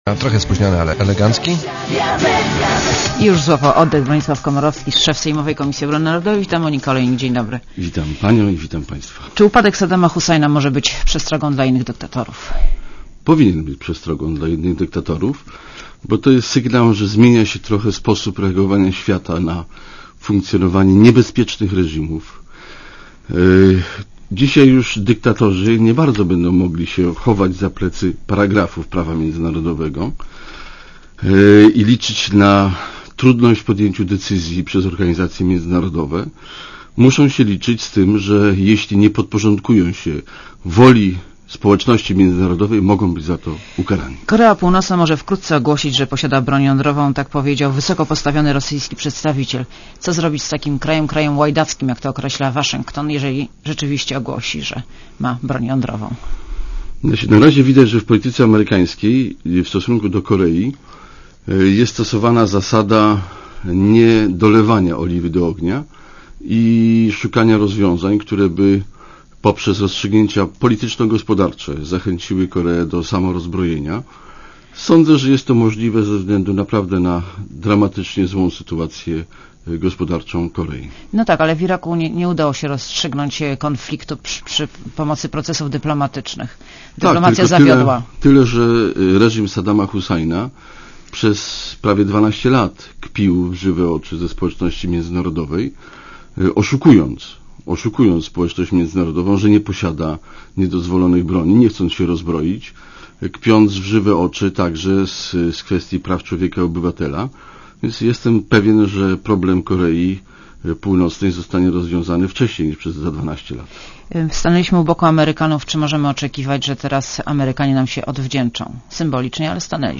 Monika Olejnik rozmawia z Bronisławem Komorowskim z Platformy Obywatelskiej